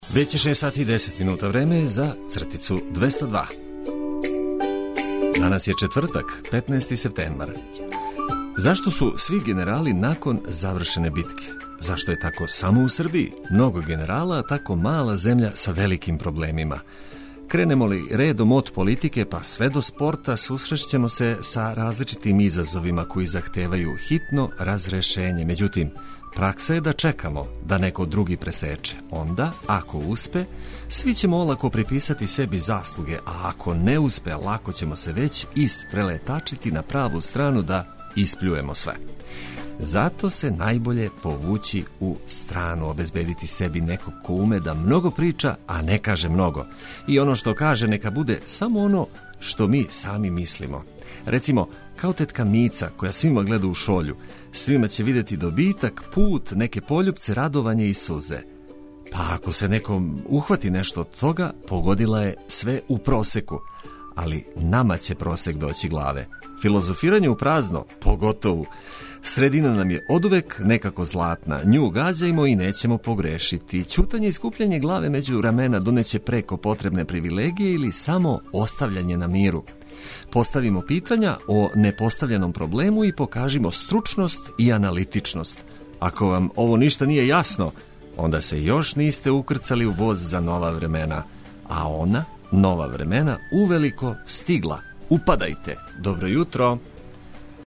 Одлична музика и овога јутра незаобилазан је фактор разбуђивања.